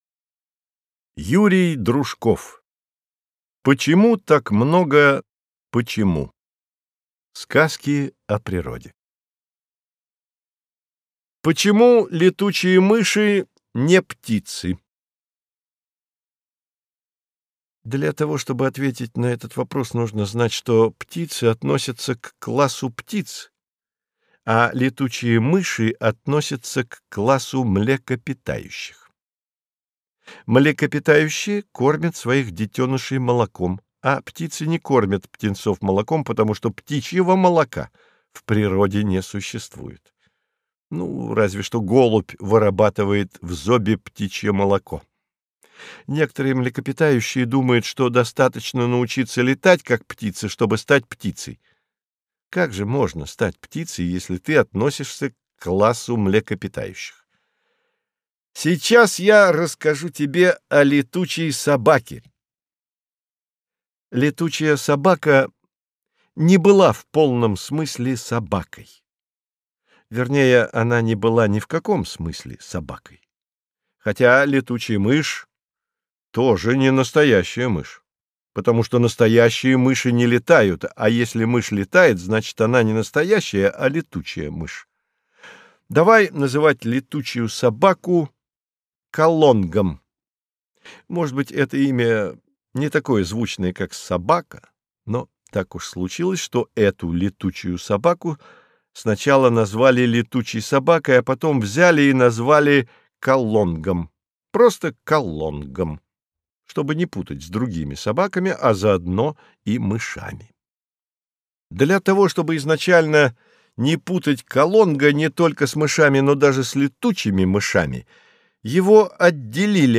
Аудиокнига Почему так много «почему». Сказки о природе | Библиотека аудиокниг